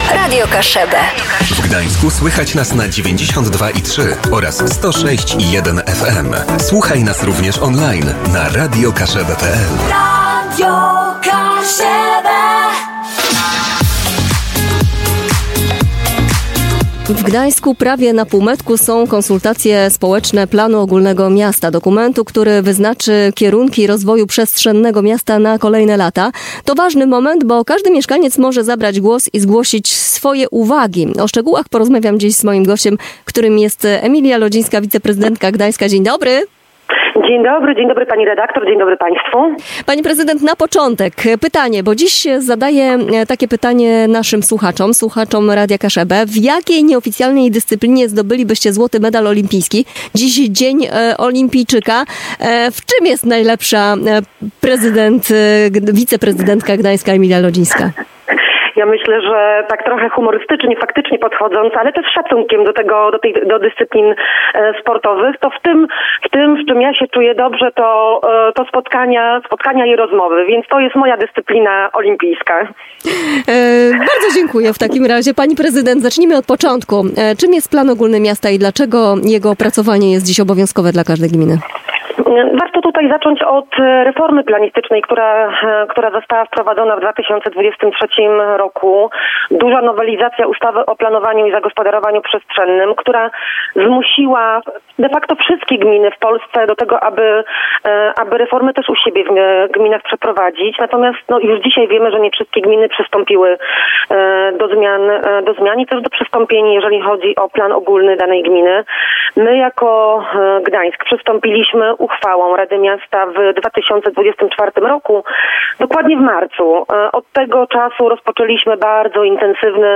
Audycja: Gość Dzień Dobry Kaszuby Rozmawiali